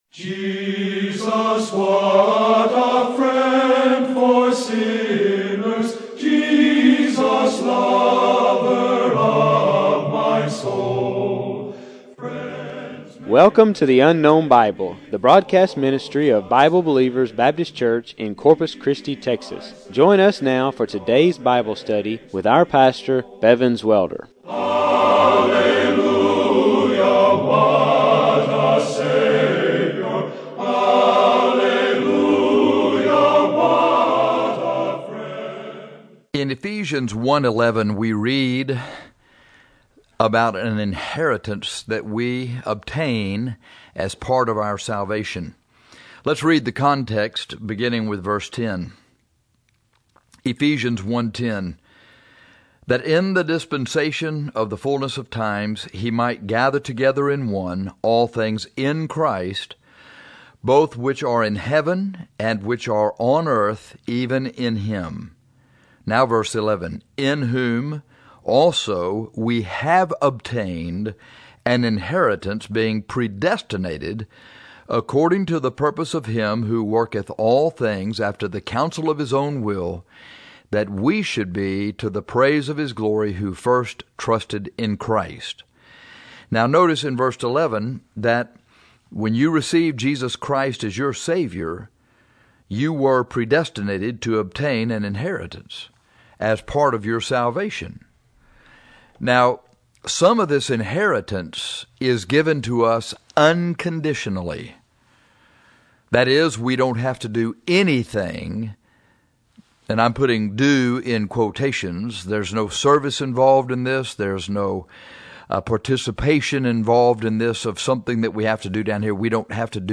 This broadcast is about inheritance prerequisites.